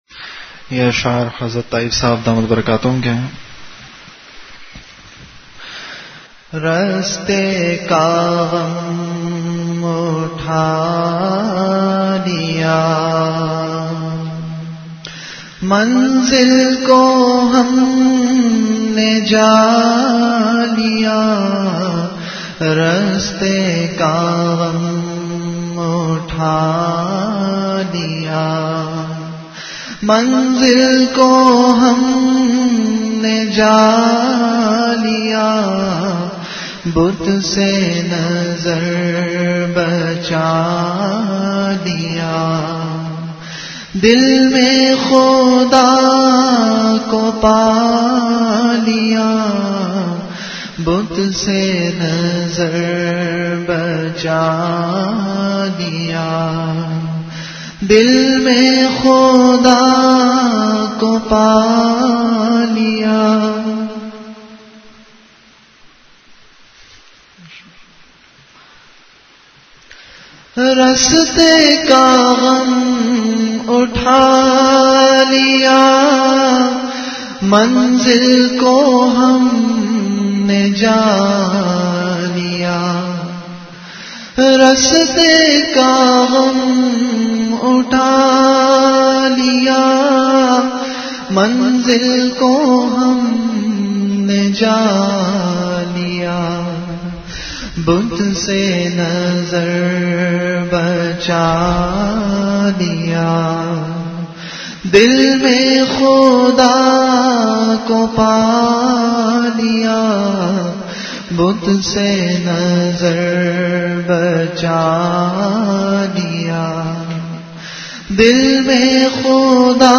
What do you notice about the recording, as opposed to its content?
Delivered at Home. Event / Time After Isha Prayer